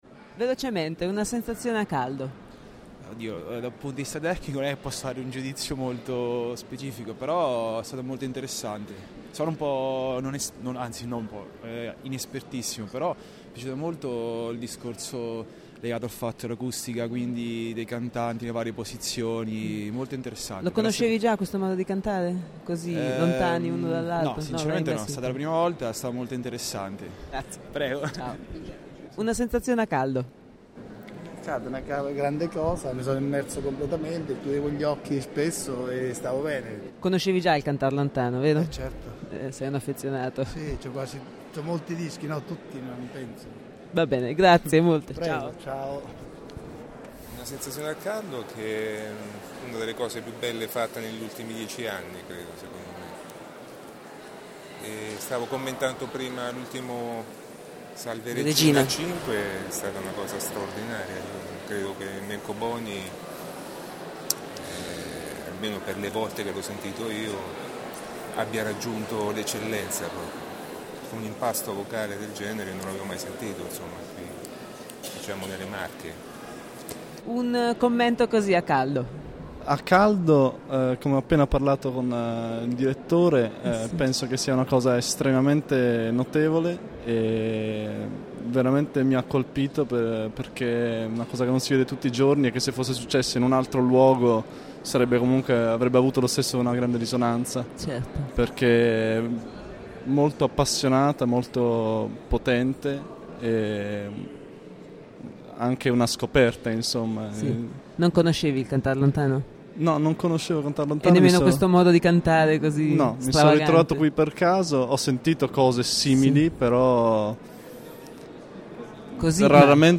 Ascoltate le sensazioni a caldo di chi usciva dalla chiesa dopo l’ultima performance del Cantar Lontano.